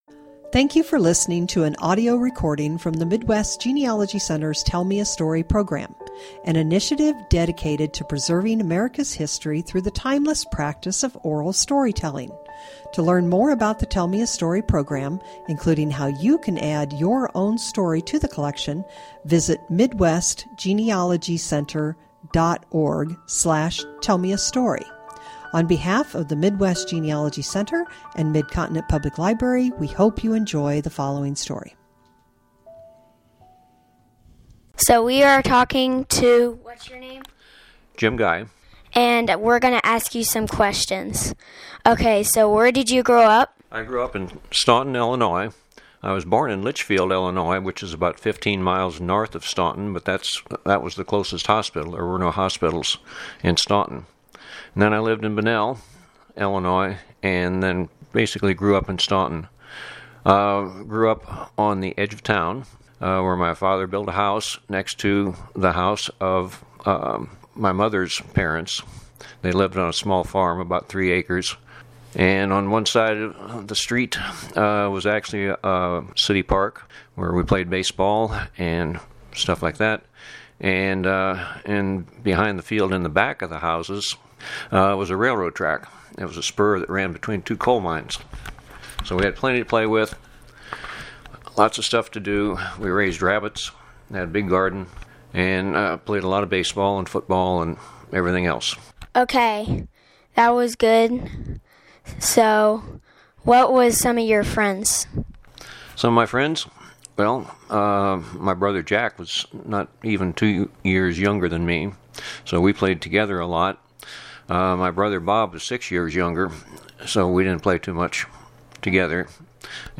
Oral Interview